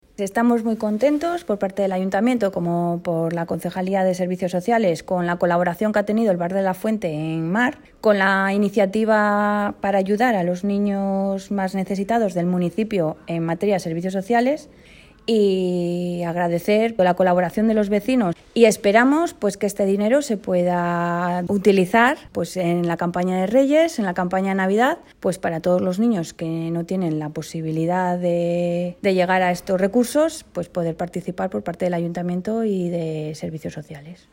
Concejal-Servicios-Sociales-sobre-donacion-vecinos-de-Mar.mp3